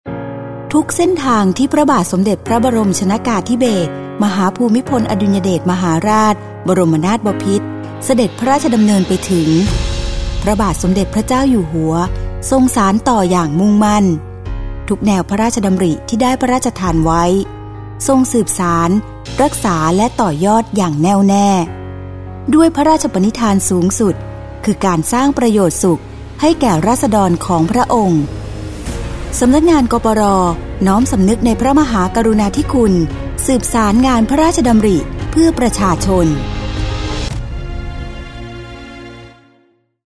ปี 2567 : สปอตประชาสัมพันธ์ ตอนที่ 6 ทรงสืบสาน รักษา และต่อยอด